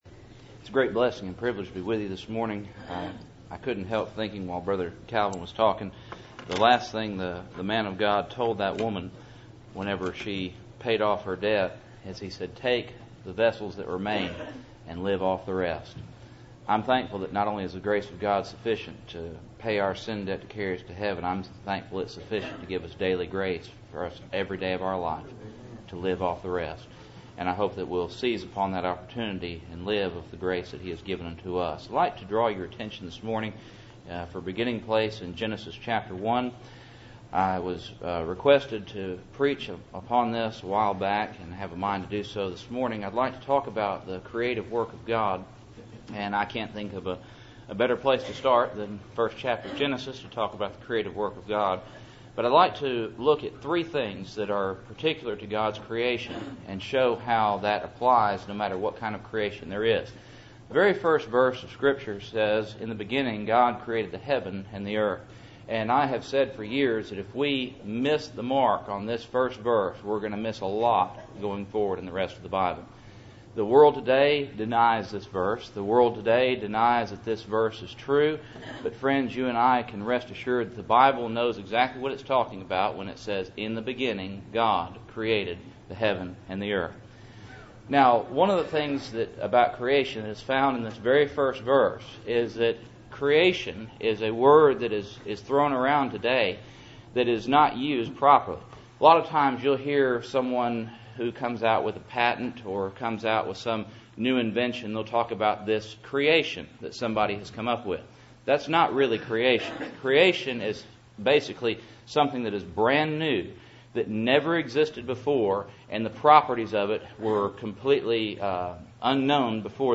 Service Type: Cool Springs PBC Sunday Morning